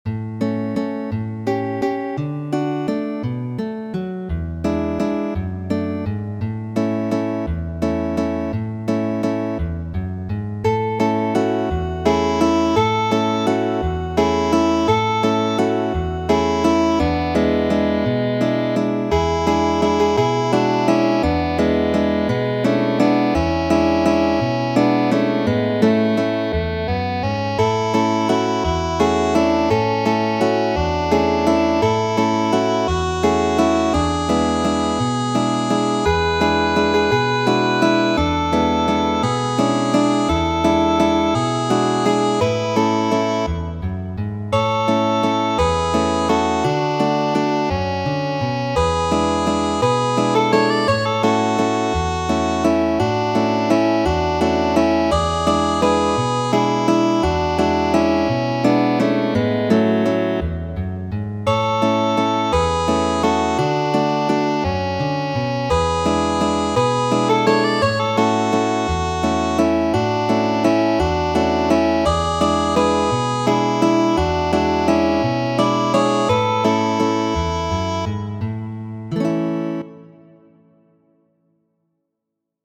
Muziko:
Linda ĉieleto, la fama meksika kanto variita de mi mem.